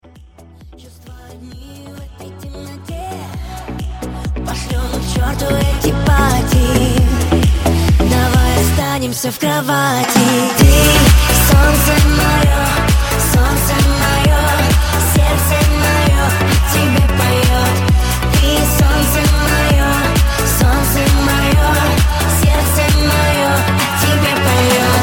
• Качество: 128, Stereo
поп
remix
нарастающие
цикличный